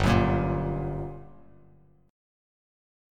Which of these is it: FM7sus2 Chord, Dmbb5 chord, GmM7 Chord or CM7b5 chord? GmM7 Chord